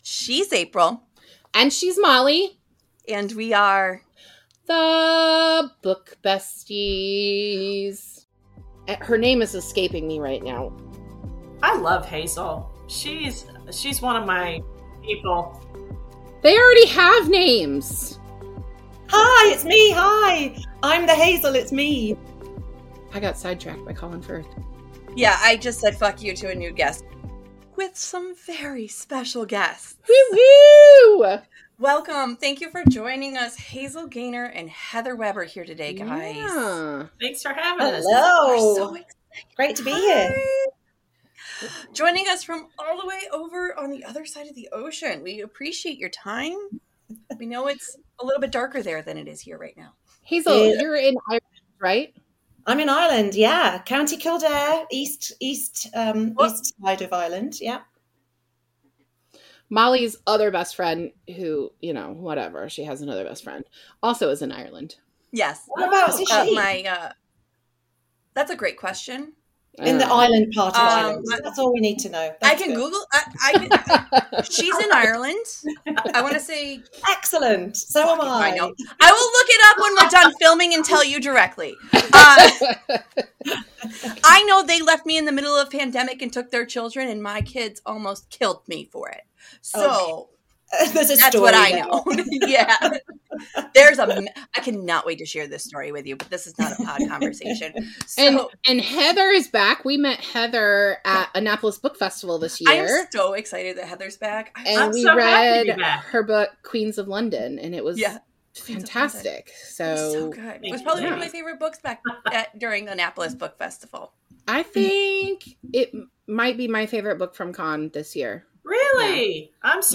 Join the four as they laugh, talk about the writing process, and (of course) Christmas.